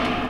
0210 DR.LOOP.wav